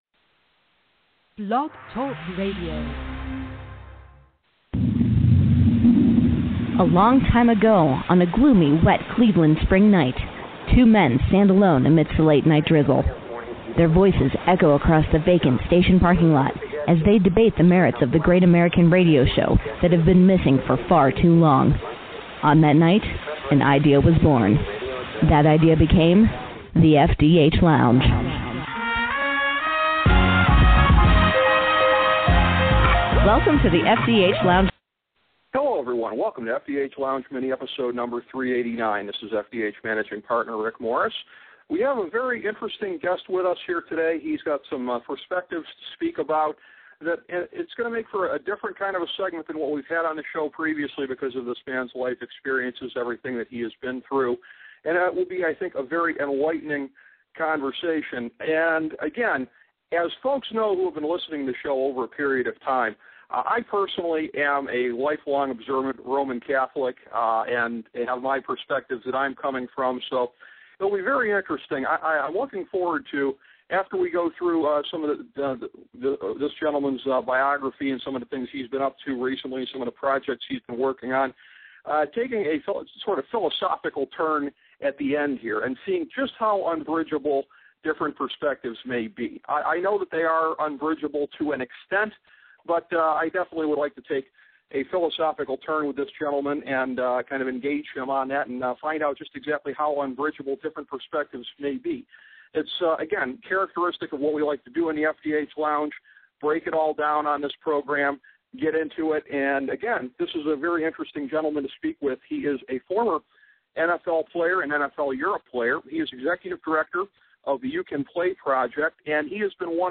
A conversation with Wade Davis